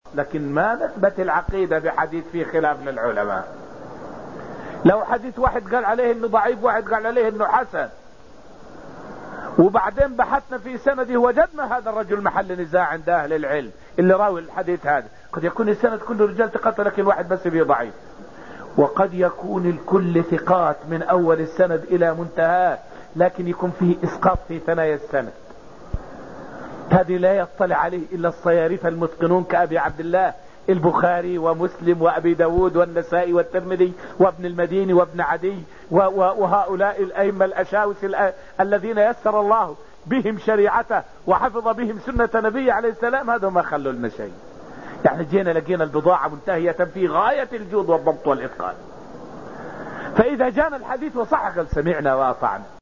فائدة من الدرس الحادي عشر من دروس تفسير سورة النجم والتي ألقيت في المسجد النبوي الشريف حول هل نثبت العقائد بحديث فيه خلاف بين أهل العلم.